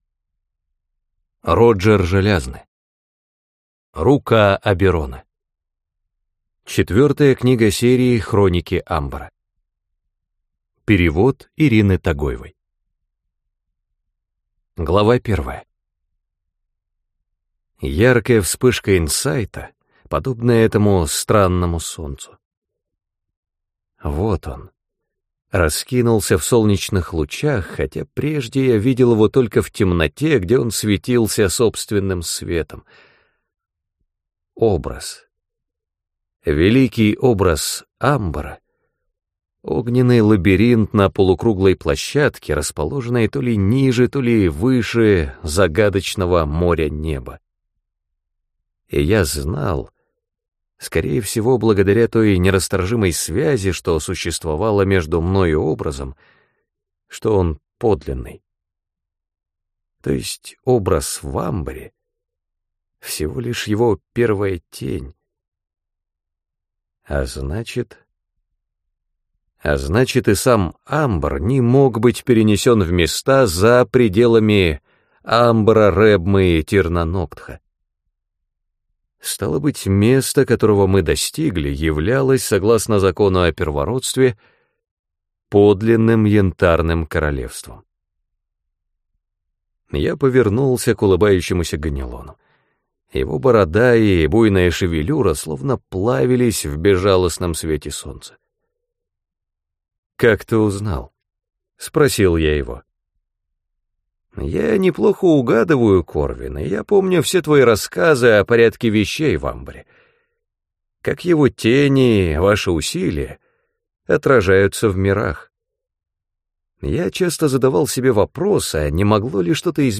Аудиокнига Рука Оберона | Библиотека аудиокниг